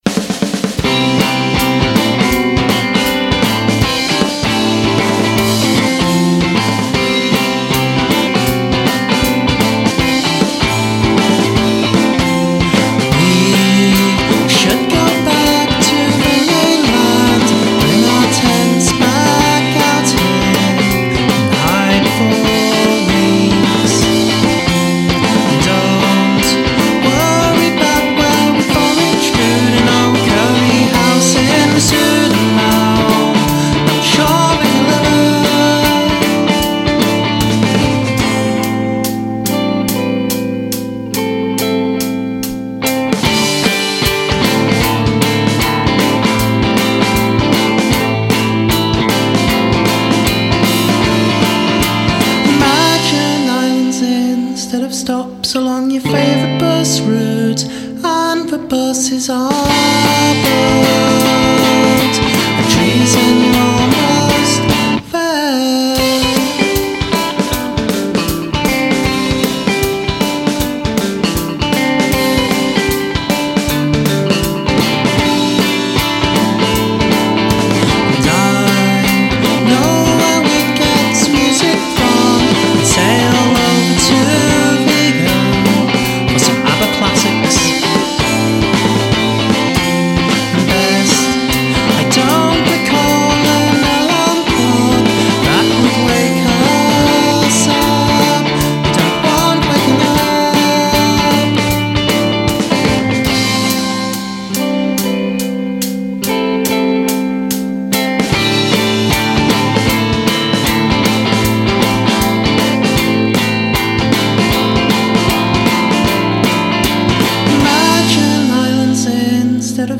suonano un classico jangle pop molto melodico